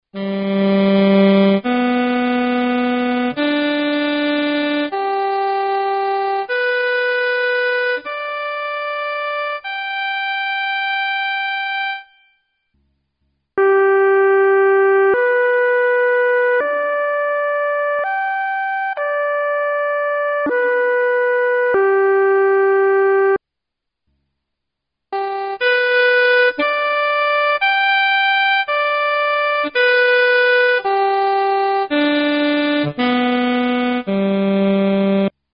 It too produced the buzzing/tremolo, and an occasional large vibrato.
Good/Bad Notes Buzzing